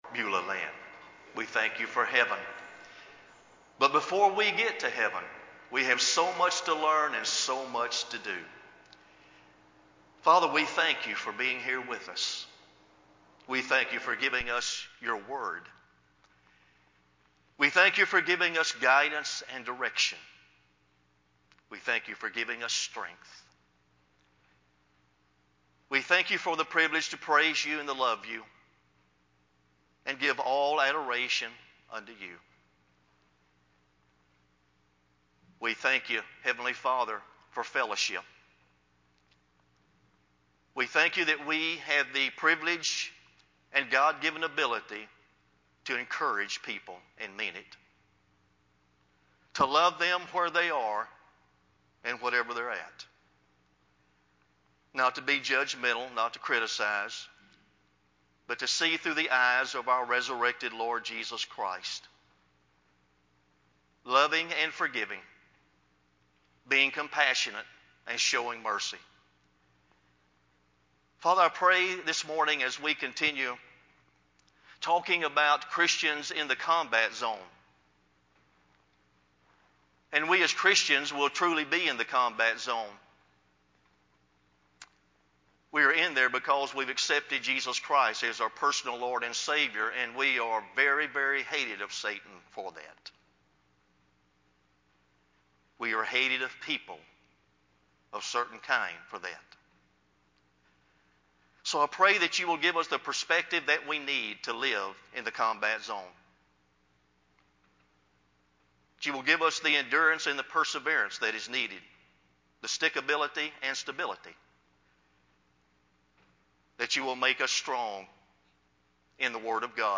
sermon-10-4-CD.mp3